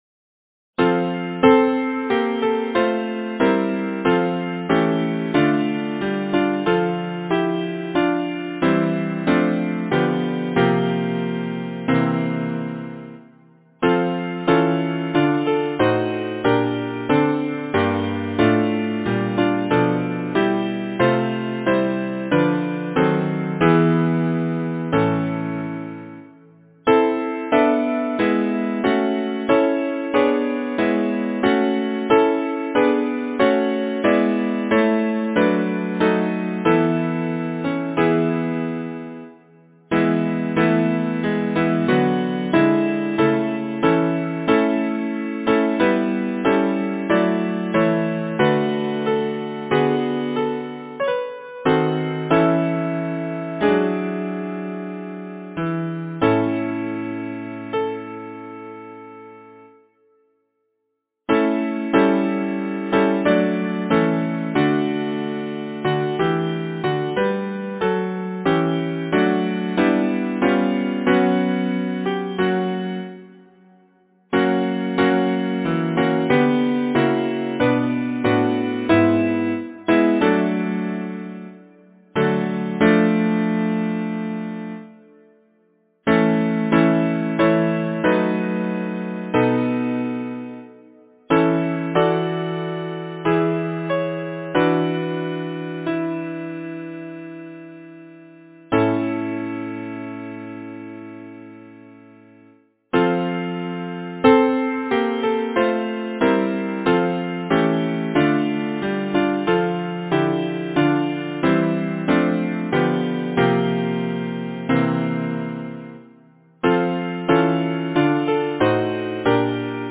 Title: At the Church Gate Composer: Frank Wilson Parish Lyricist: William Makepeace Thackeray Number of voices: 4vv Voicing: SATB Genre: Secular, Partsong
Language: English Instruments: A cappella